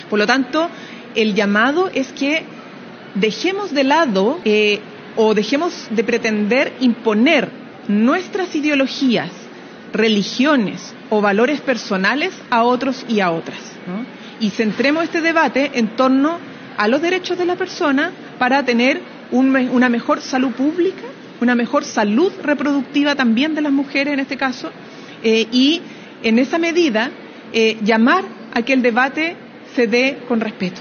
Durante una vocería, Vallejo subrayó que “el aborto es una realidad en nuestro país, pero ocurre en condiciones inseguras. Por eso es necesario regularlo, para que sea seguro y forme parte de una política de salud pública que proteja a las mujeres”.